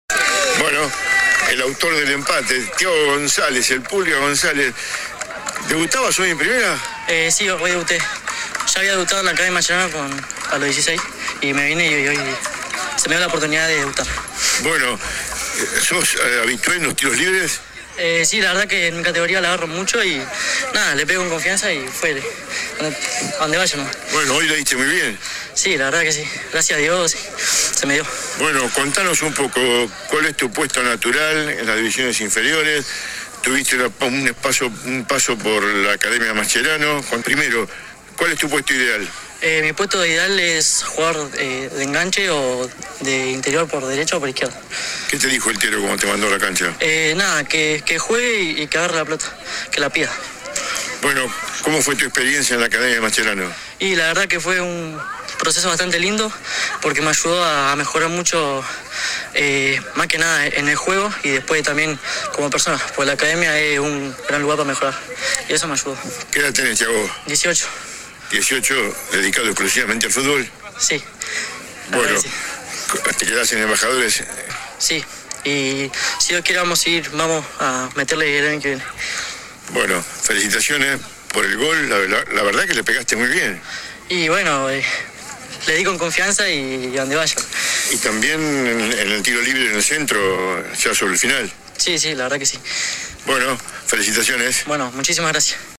Terminado el cotejo dialogó con nuestro portal.
AUDIO DE LA ENTREVISTA